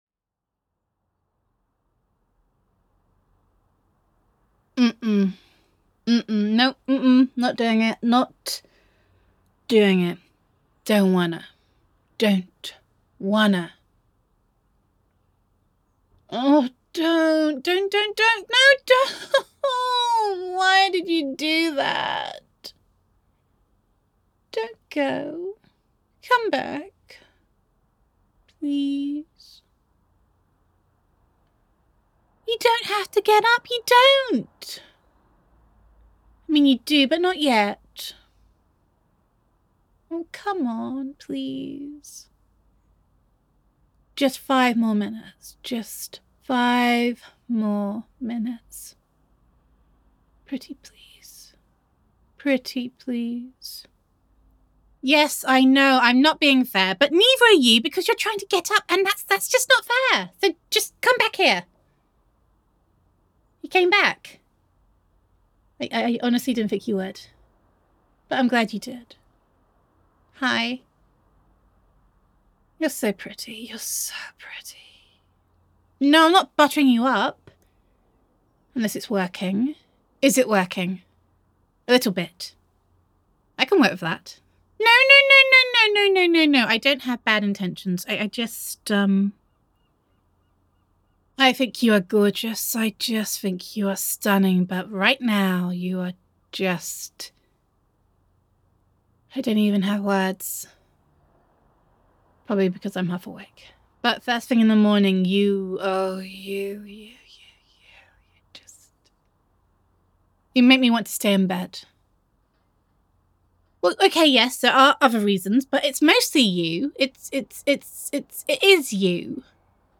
[F4A] Can We Just Stay In Bed Forever?